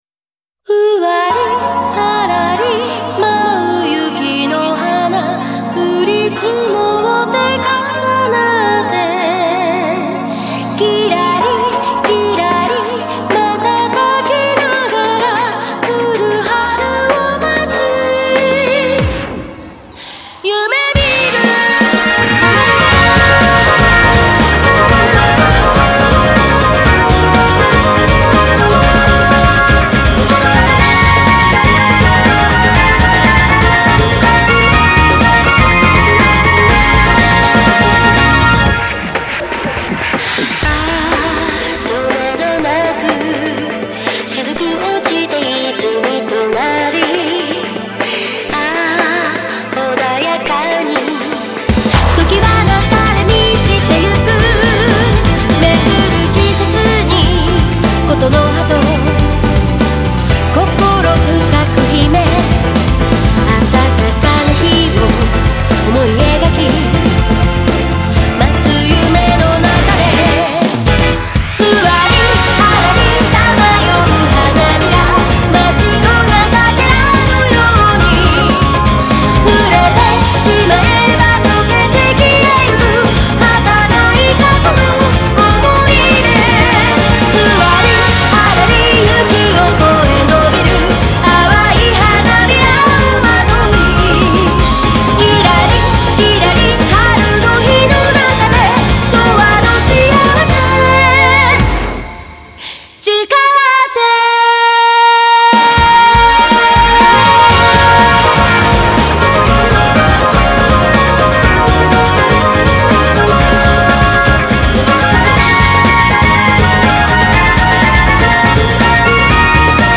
主題歌